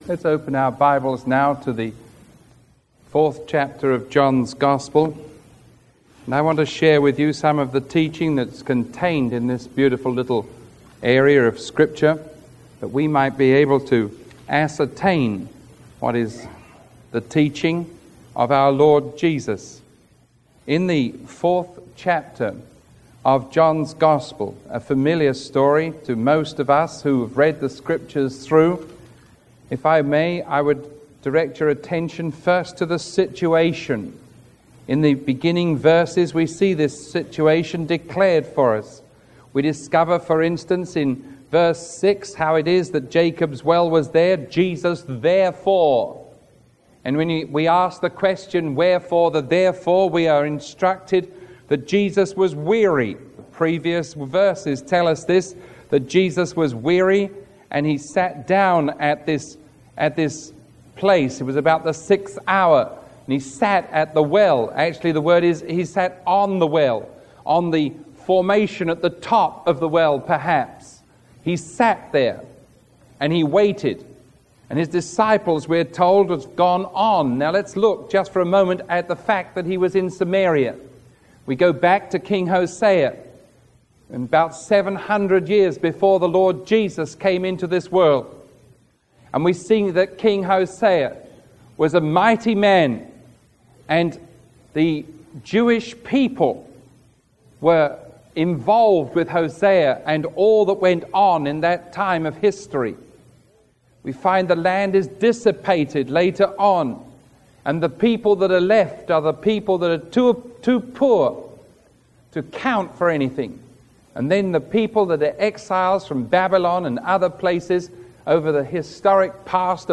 Sermon 0344AB recorded on May 3